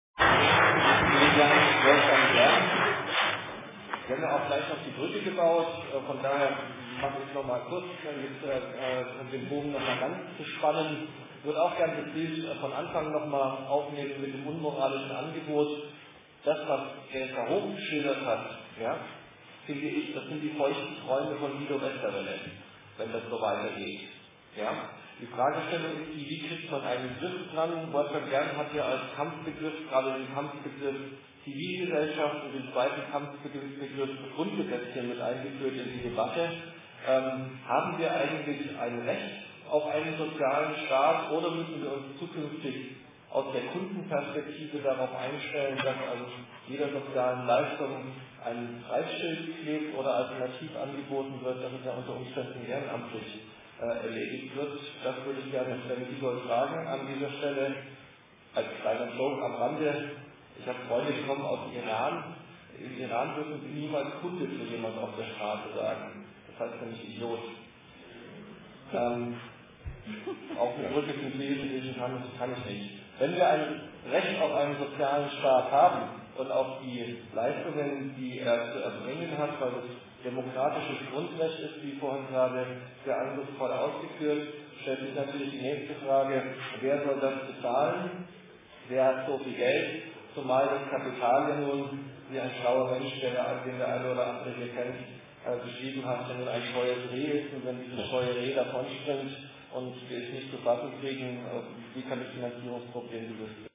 Attac-Frankfurt, das Bündnis gegen Privatisierung, IG Metall Ffm, GEW BV-Ffm und ver.di Hessen FB Kommunen hatten ins Gewerkschaftshaus eingeladen.
Moderation
Teil 1: Vorträge (komplett)